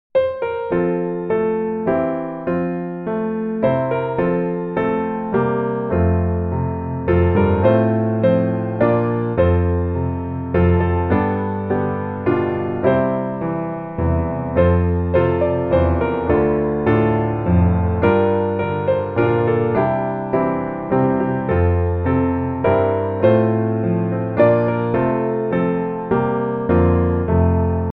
Gospel
F Majeur